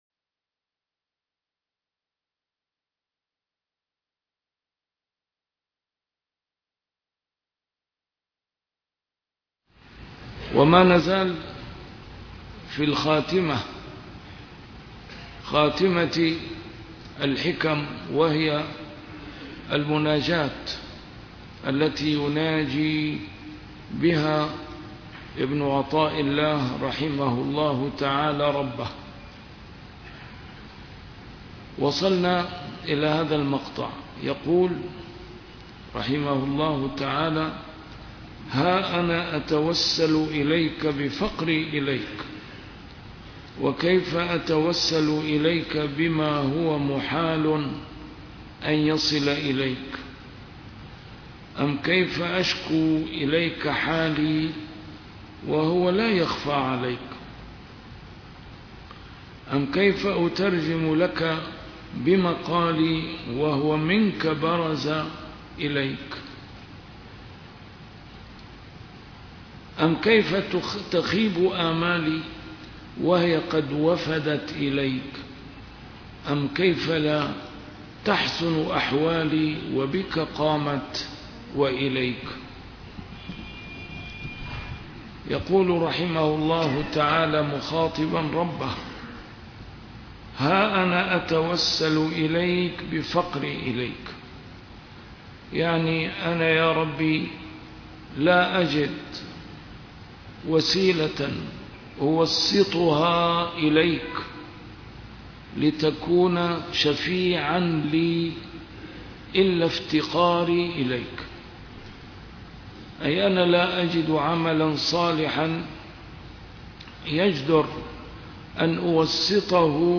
A MARTYR SCHOLAR: IMAM MUHAMMAD SAEED RAMADAN AL-BOUTI - الدروس العلمية - شرح الحكم العطائية - الدرس رقم 295 شرح الحكمة رقم 264